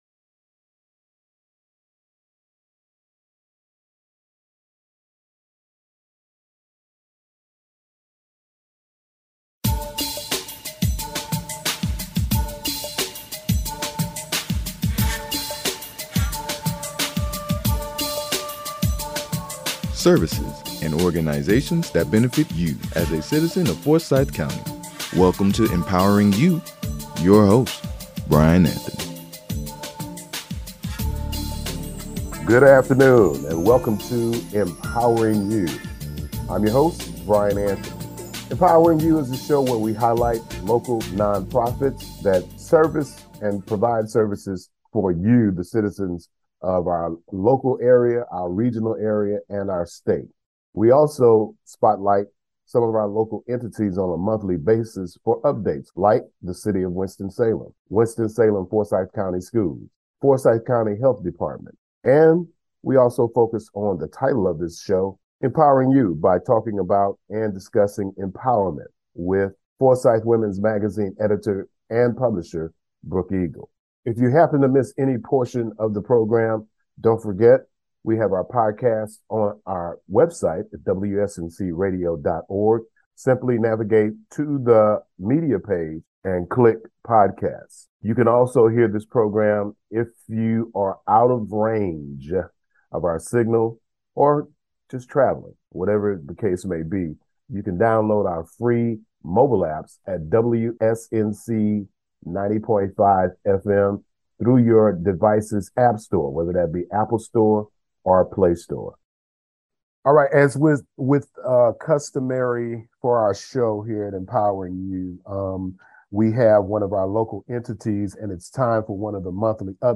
Empowering You is a 1-hour broadcast produced and recorded in the WSNC-FM Studios. The program is designed to highlight Non-Profit organizations, corporate community initiatives that are of benefit to the citizens of our community.